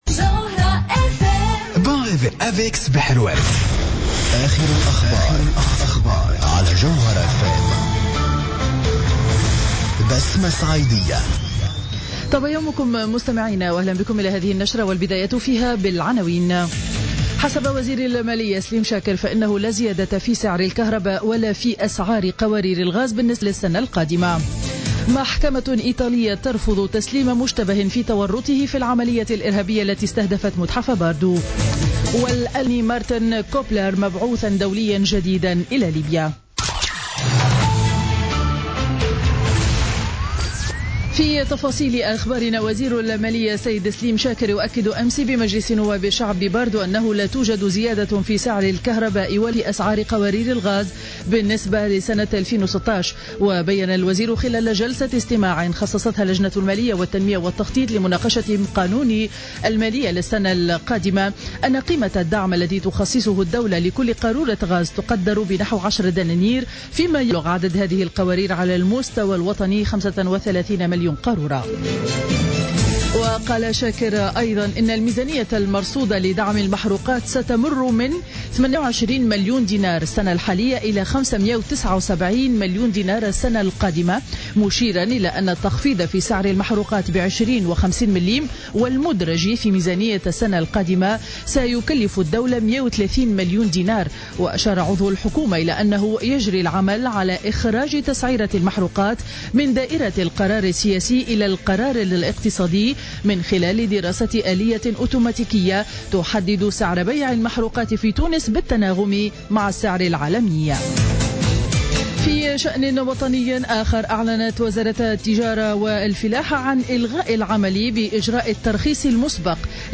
نشرة أخبار السابعة صباحا ليوم الخميس 29 أكتوبر 2015